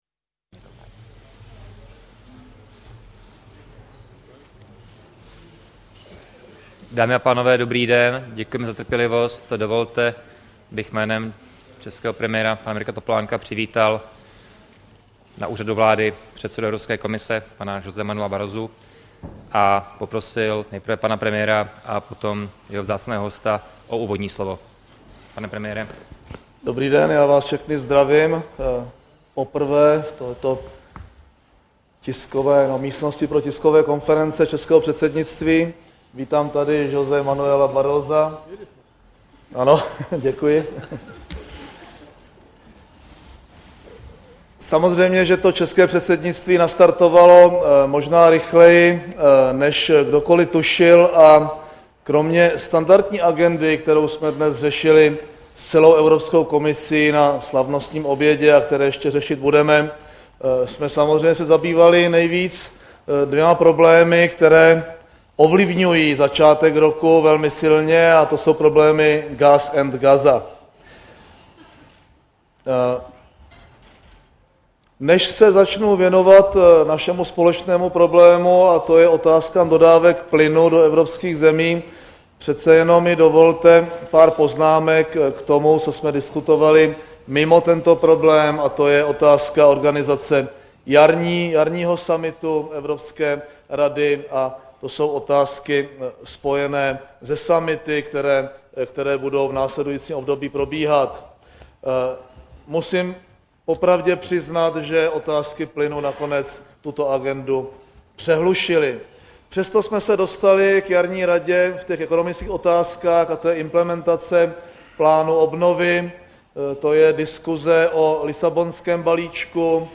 Tisková konference předsedy vlády ČR Mirka Topolánka a předsedy Evropské komise José Manuela Borrosa 7. 1. 2009